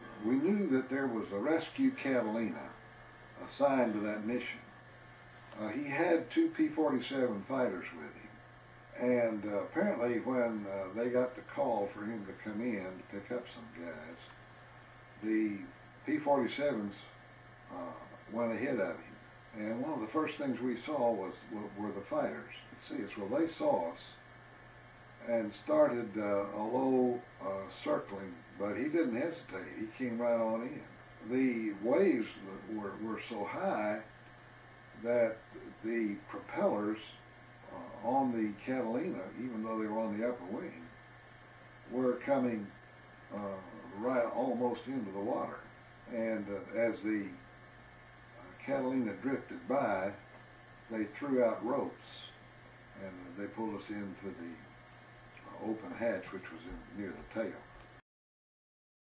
and interview with his father.